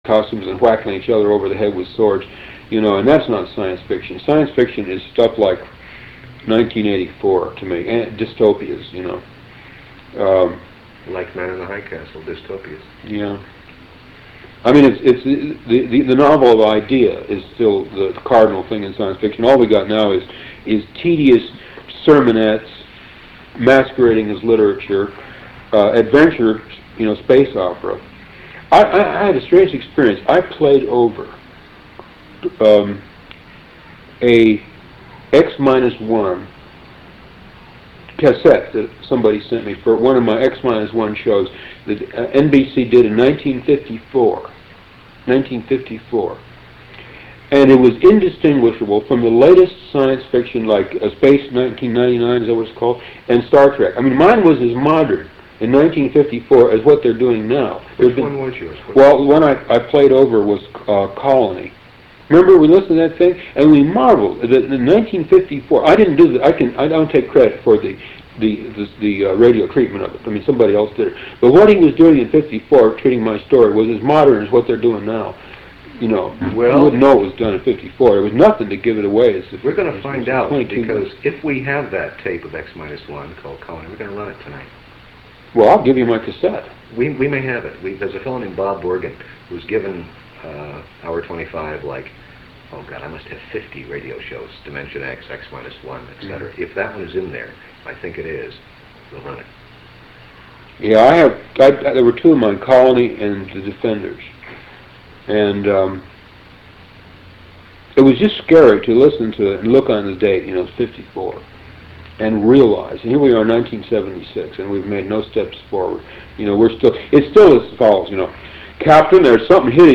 Interview with Philip K Dick 10